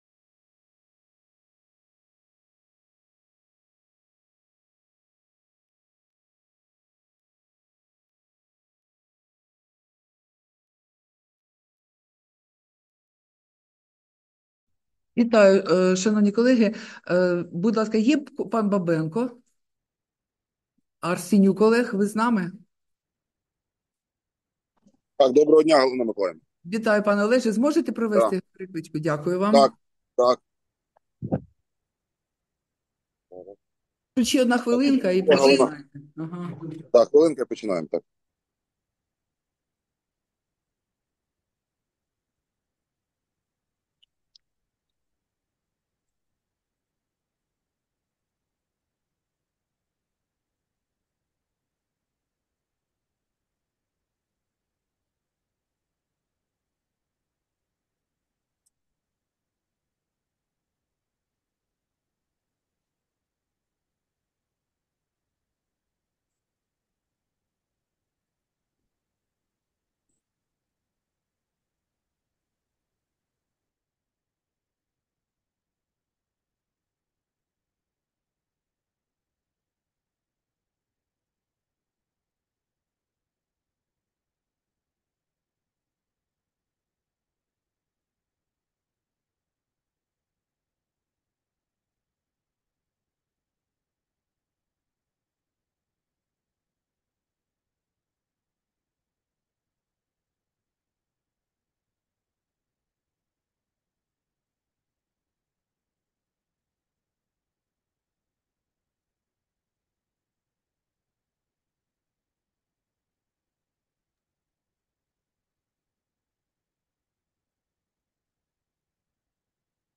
Засідання Комітету від 14 червня 2024 року